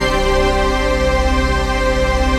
DM PAD2-25.wav